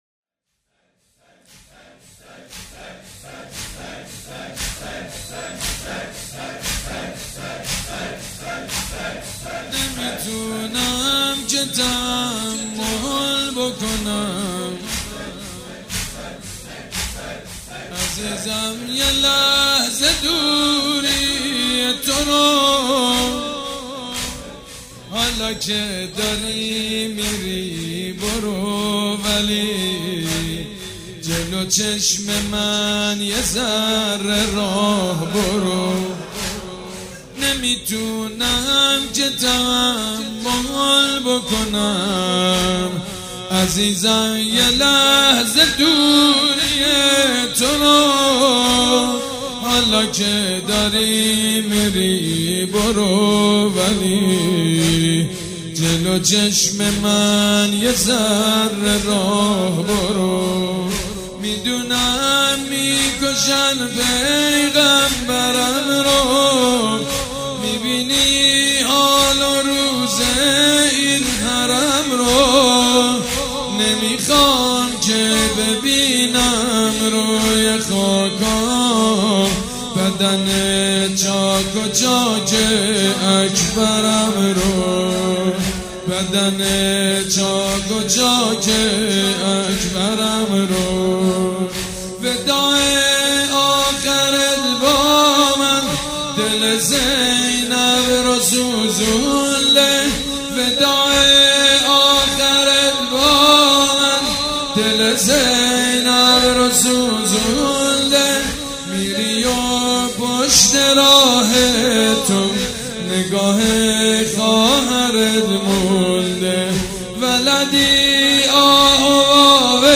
مراسم شب هشتم محرم الحرام سال 1395 با نوای مجید بنی فاطمه.